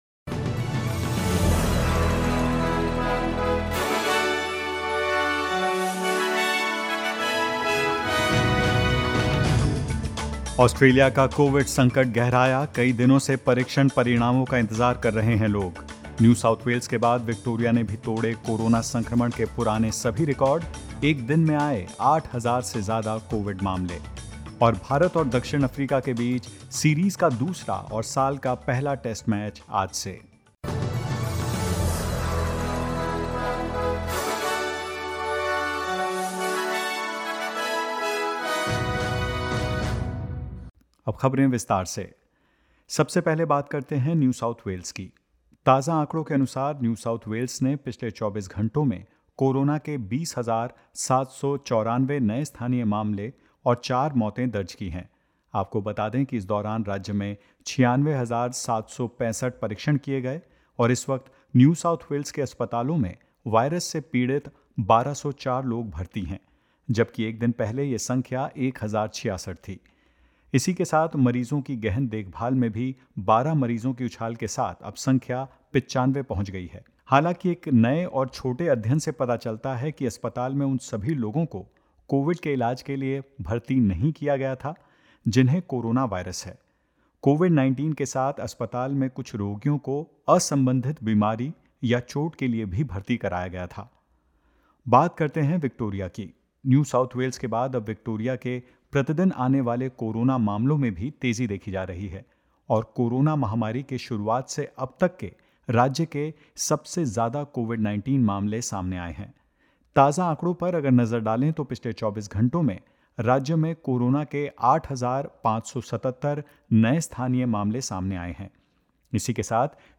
In this latest SBS Hindi news bulletin: Prime Minister resisting calls to make Rapid Antigen Tests free for the public; Health authorities across the country pleading with citizens not to overwhelm hospitals as Omicron continues to spread and more.